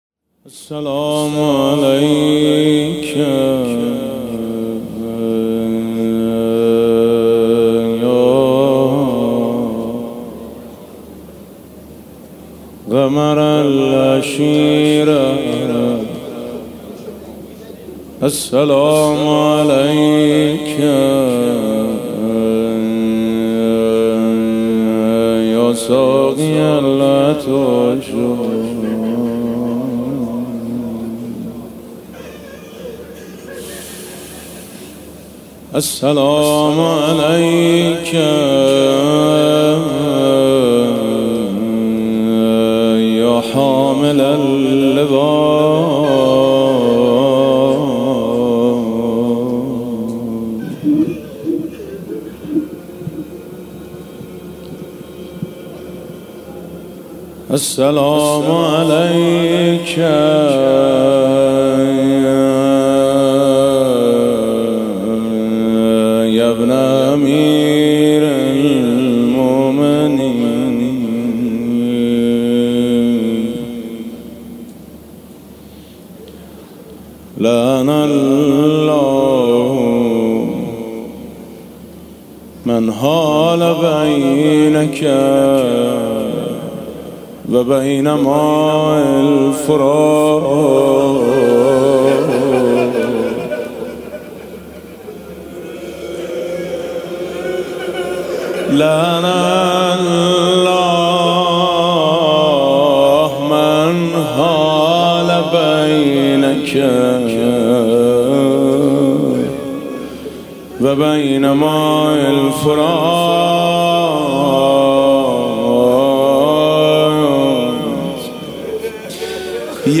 سخنرانی: محبت به امام موجب ابدی شدن اعمال خیر